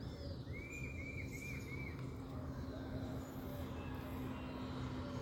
Saci (Tapera naevia)
Nome em Inglês: Striped Cuckoo
Detalhada localização: Termas de Río Hondo
Condição: Selvagem
Certeza: Gravado Vocal